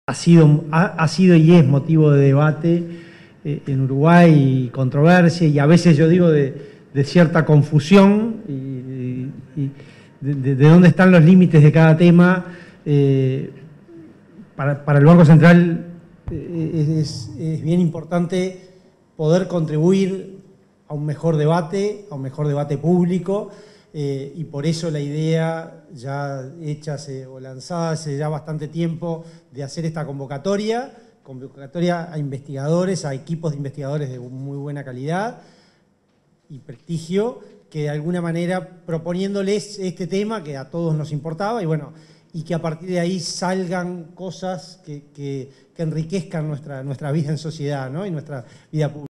Palabras del presidente del Banco Central del Uruguay (BCU), Diego Labat
En el marco de la presentación de una investigación para determinar las causas del nivel de precios en el país, 1 de febrero, se expresó el presidente